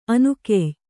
♪ anukey